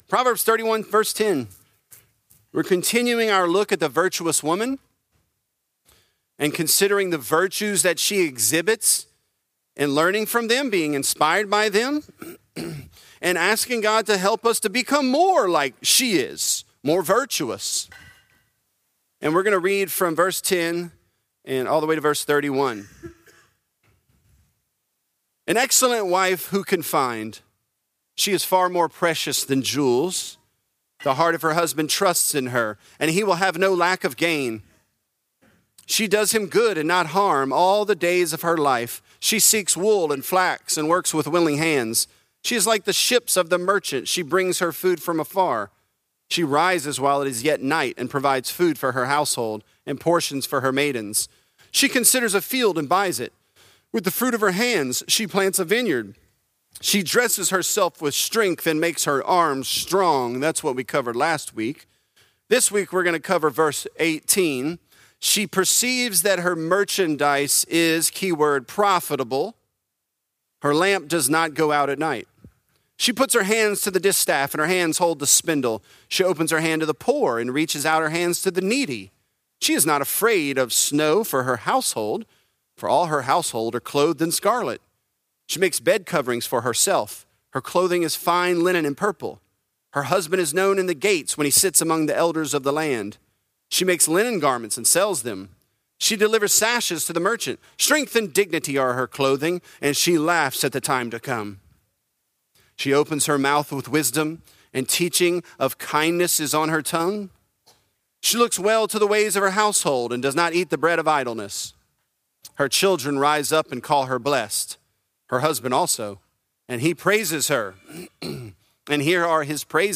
Virtuous: Her Merchandise is Profitable | Lafayette - Sermon (Proverbs 31)